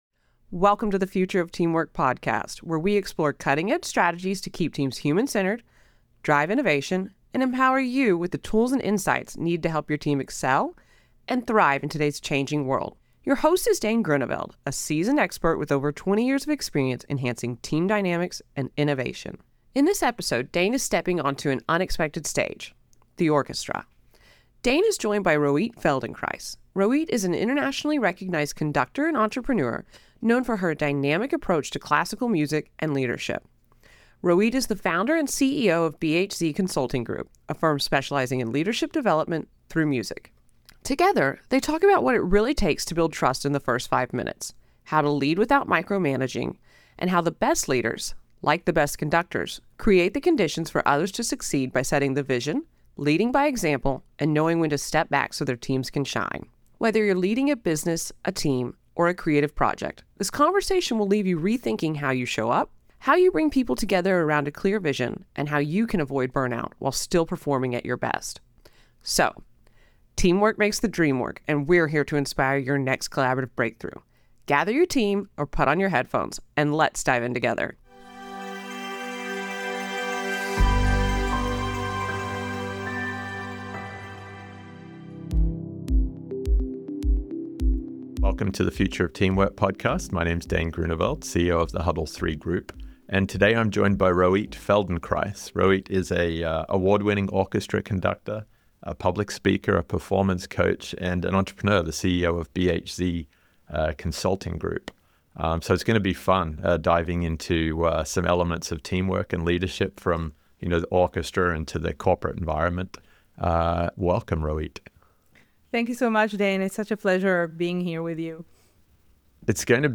Whether you’re the CEO of a business or leading a team of any kind, this conversation will shift how you think about leadership, trust, and teamwork.